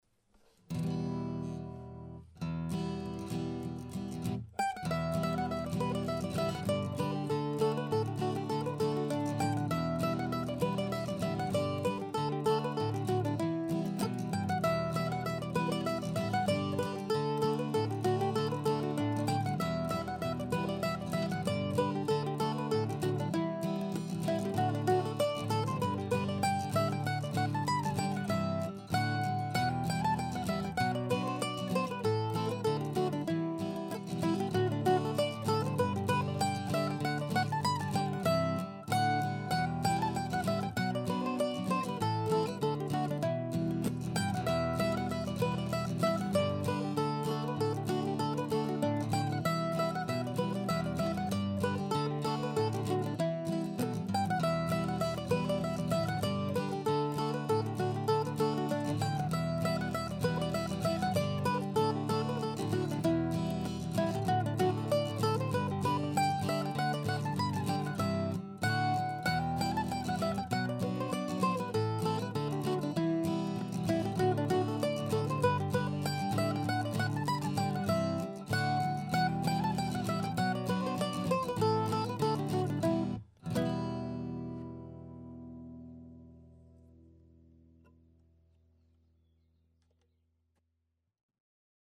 The second tune there, "Cold Market Morning", is another mid-tempo tune in Em with some real similarities.